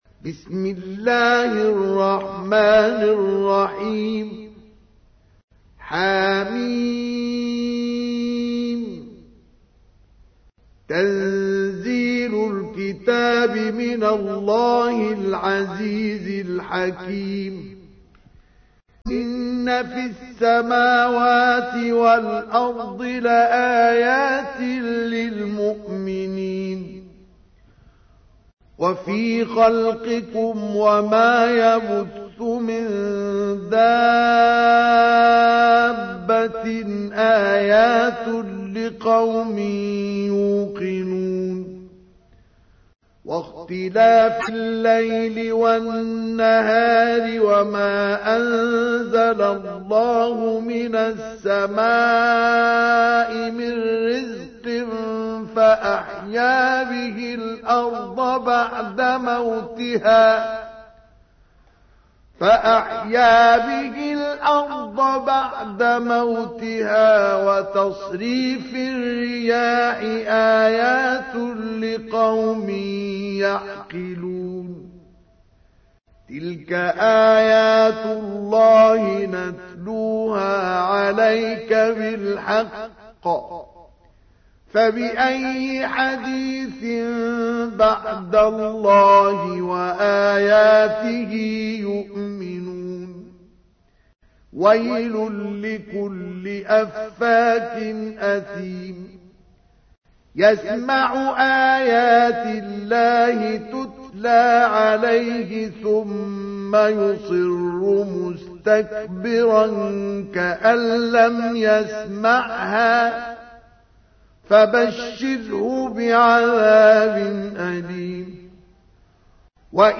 تحميل : 45. سورة الجاثية / القارئ مصطفى اسماعيل / القرآن الكريم / موقع يا حسين